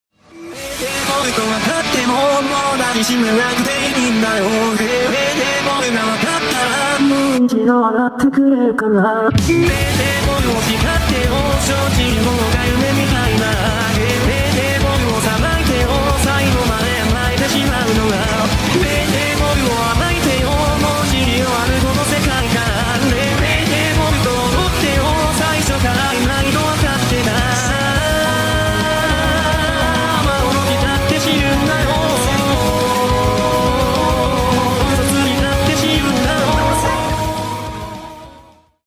Вокалоиды